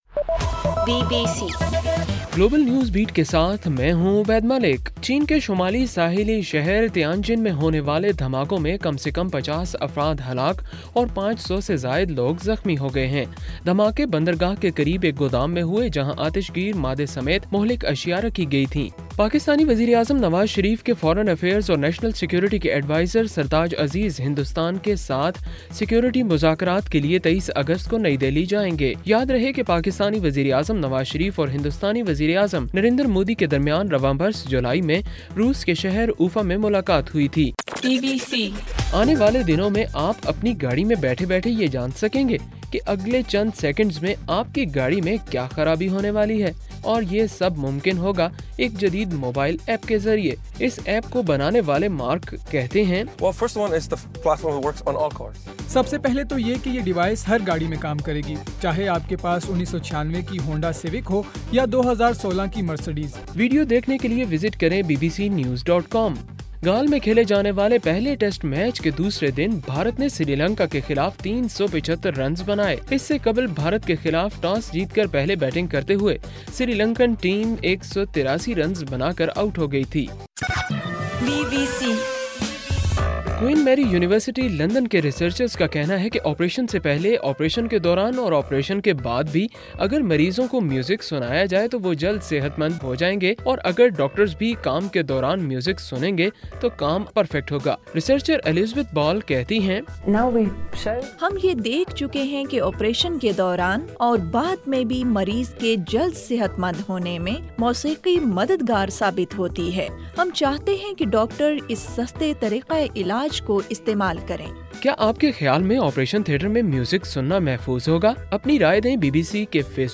اگست 13: رات 8 بجے کا گلوبل نیوز بیٹ بُلیٹن